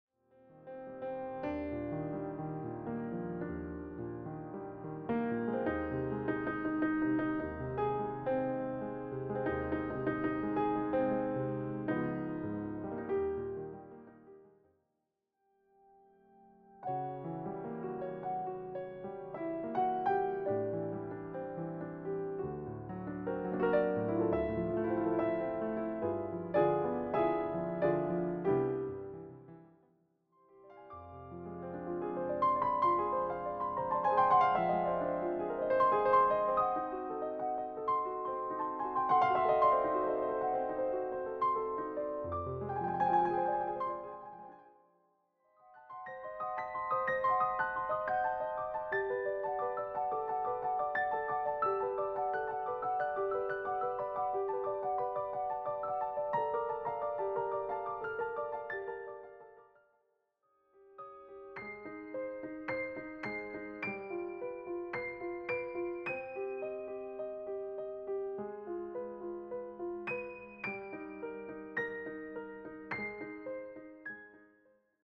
all rendered as solo piano pieces.
intimate, late-night atmosphere